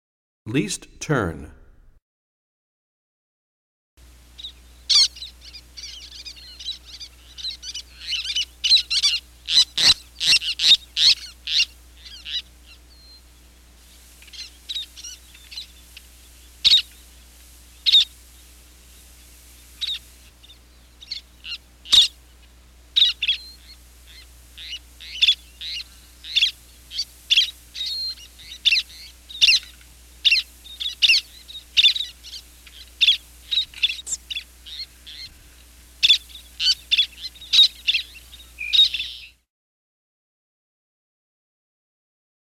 52 Least Tern.mp3